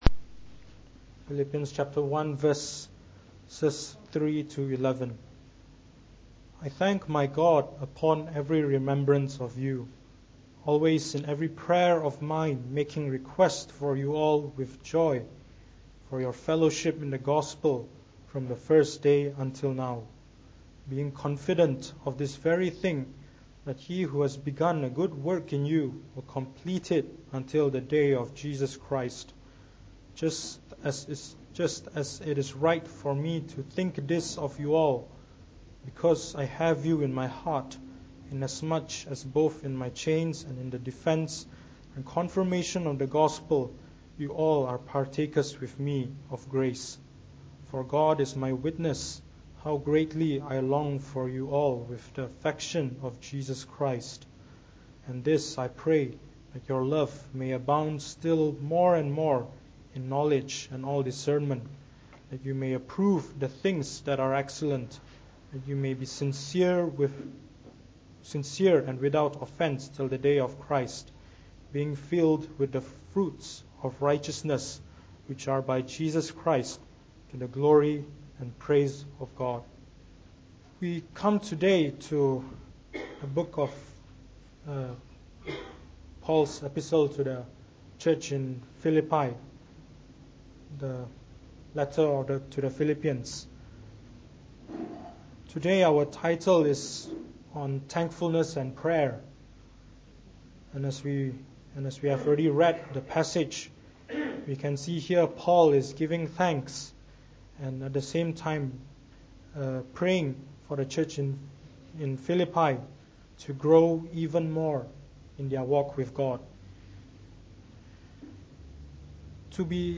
Preached on the 1st of January 2017.